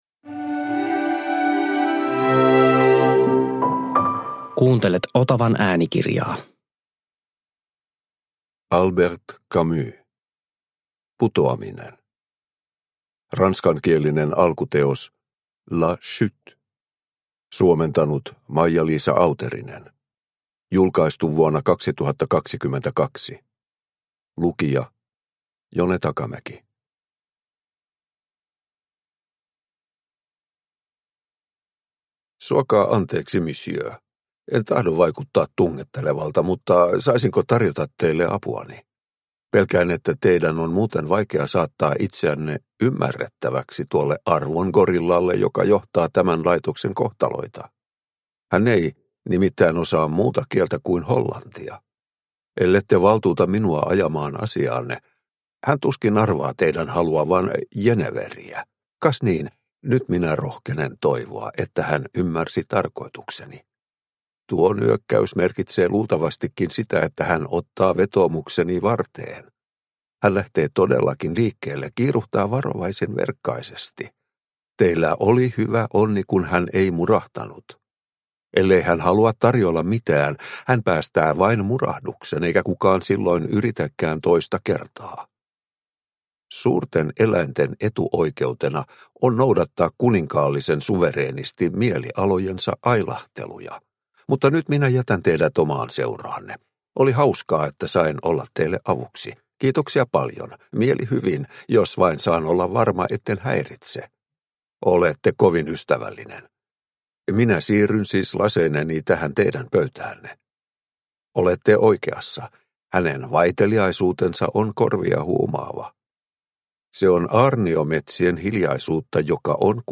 Putoaminen – Ljudbok – Laddas ner